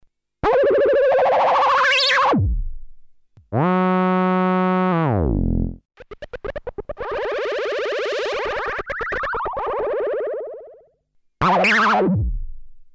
Korg MS-10
Its basic building blocks are not too exciting: 1 oscillator, 1 two pole lowpass filter, 1 envelope (HADSR, where H means Hold) and an LFO.
The actual sound of the synth is dominated by the very "screamy" filter - it's really something else. If you push the resonance to the max, the filter starts self-oscillating, and you can easily get a kind of distortion between oscillator and the "filter tone", resembling the effects of oscillator sync.